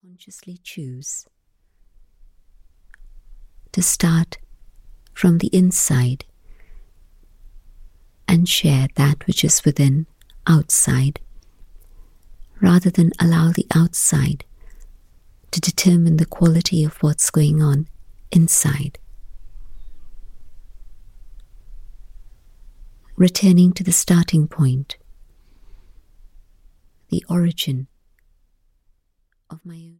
Guided meditations to calm the mind and spirit